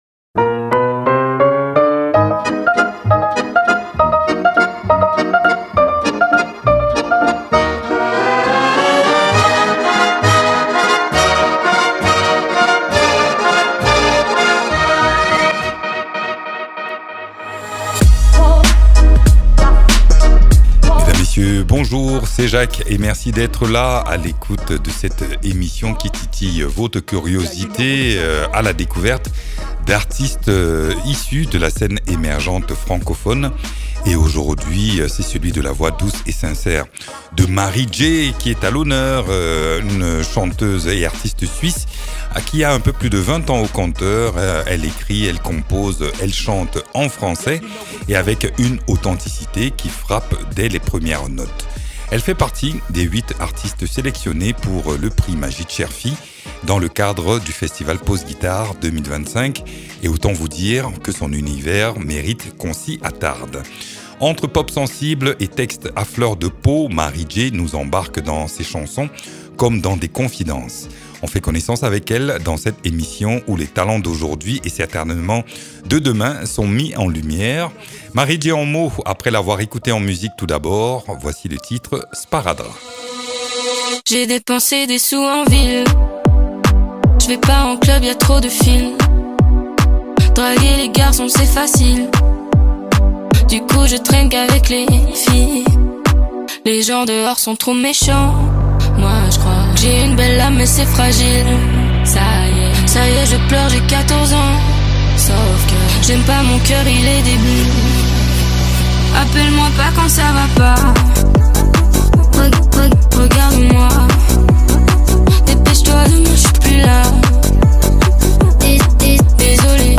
autrice-compositrice interprète.
Interviews